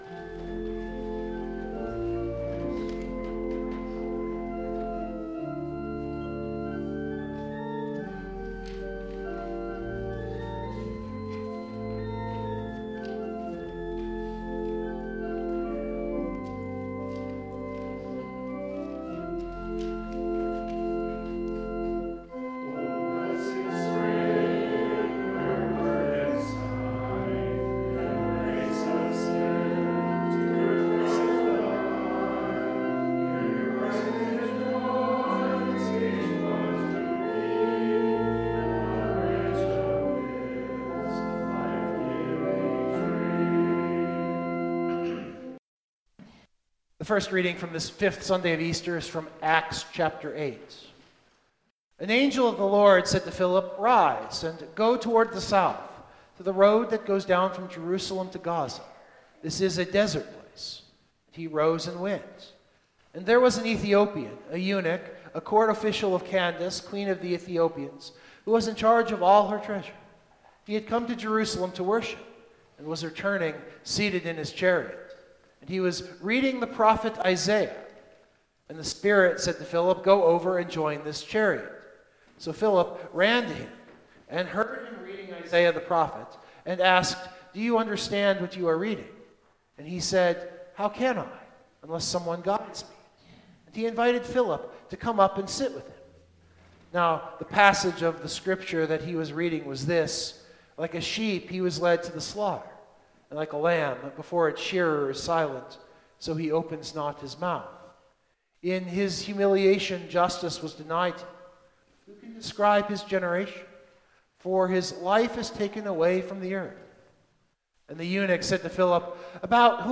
It is also why the sermon is a spoken form.